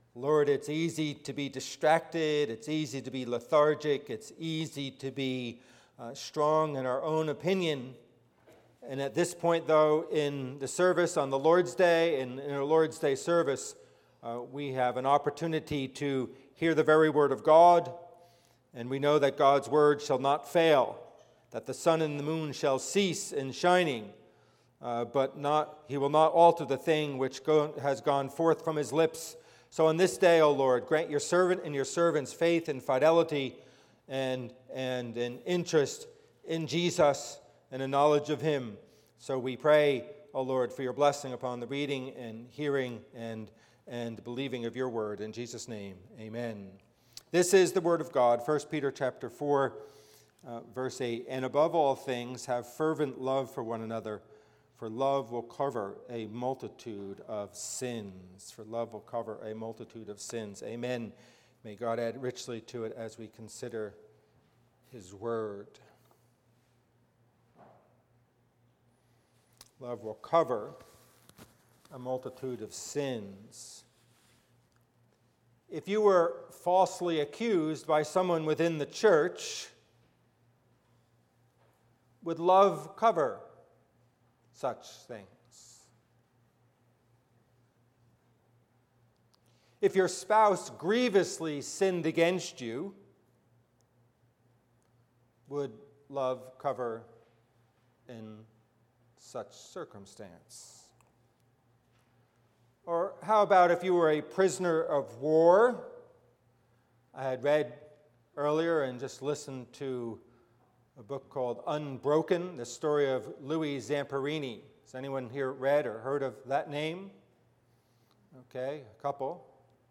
Passage: 1 Peter 4:8 Service Type: Worship Service